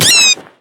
bat_death.ogg